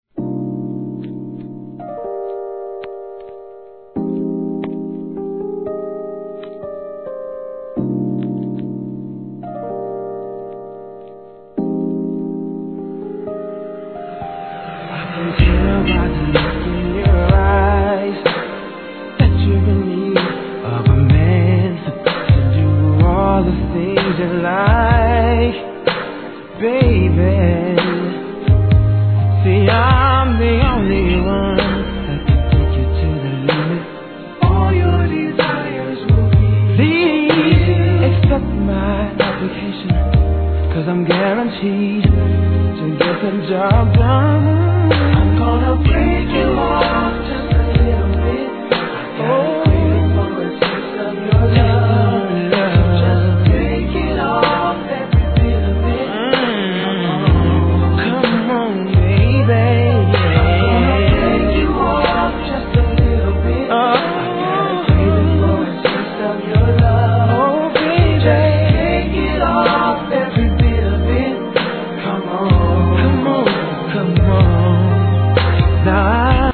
HIP HOP/R&B
GOOD男性ヴォーカル・グループ物!! SLOW JAMでじっくり聴かせます♪